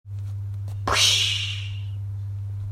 Download Exploding sound effect for free.
Exploding